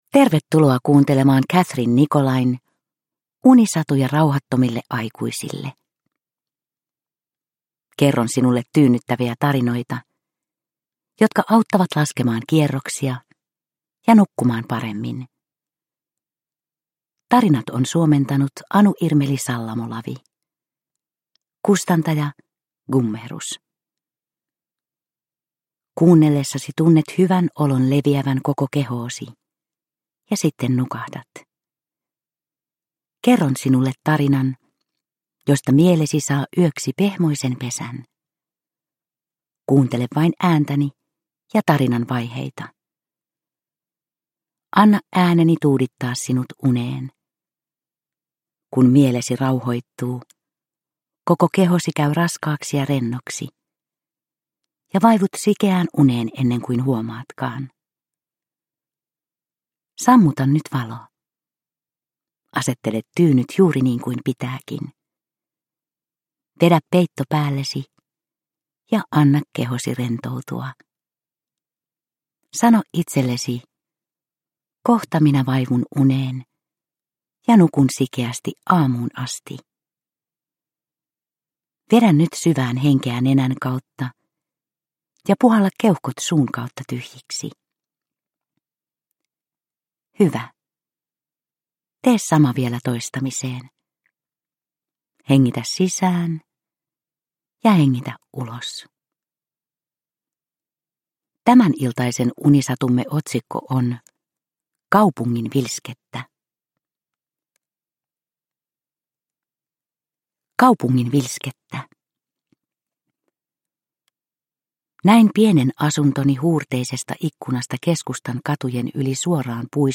Unisatuja rauhattomille aikuisille 9 - Kaupungin vilskettä – Ljudbok – Laddas ner